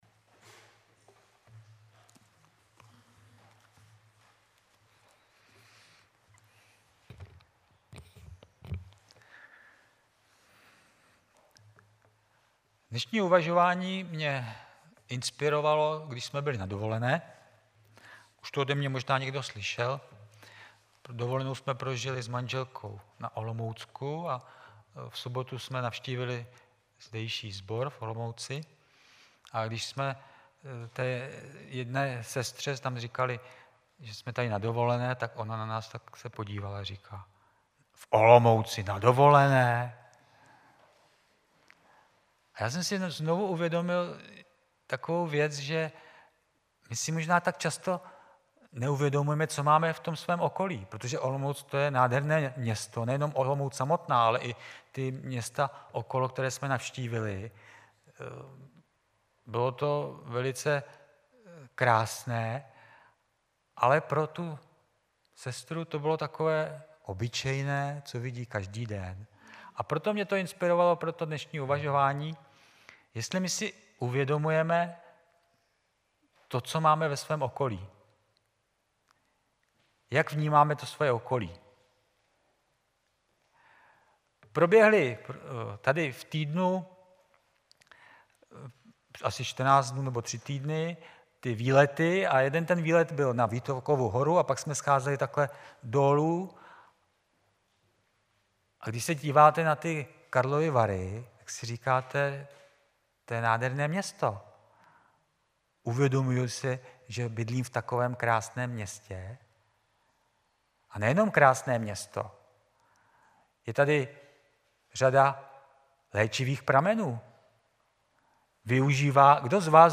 Twitter Digg Facebook Delicious StumbleUpon Google Bookmarks LinkedIn Yahoo Bookmarks Technorati Favorites Tento příspěvek napsal admin , 28.8.2016 v 10:14 do rubriky Kázání .